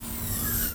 closeDoor.wav